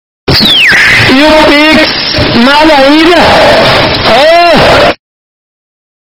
Categoria: Sons virais